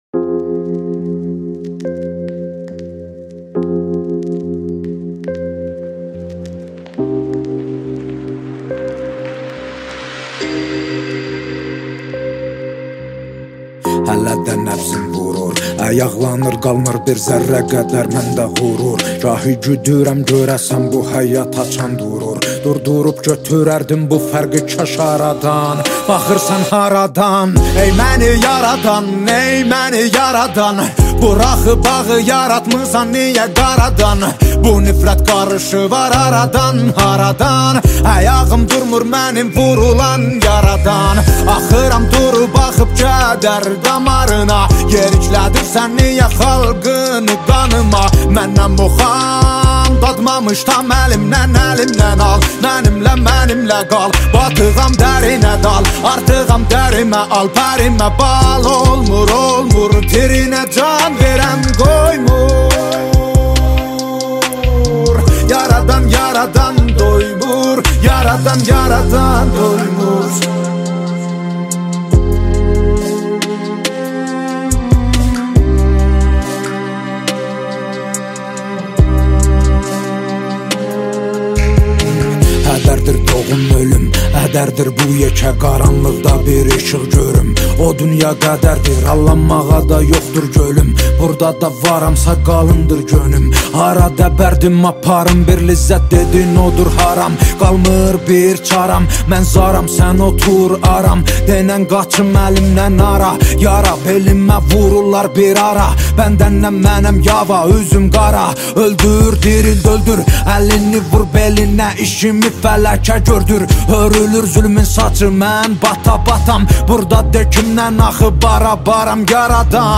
ژانر: پاپ و رپ